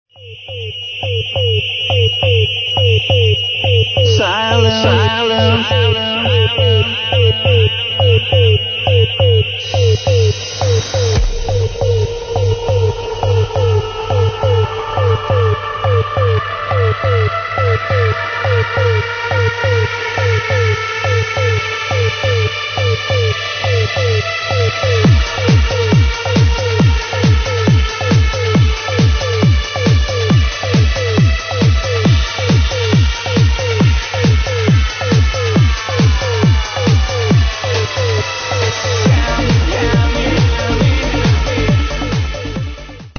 Trance Tracks PLEASE HELP TO ID !!!!!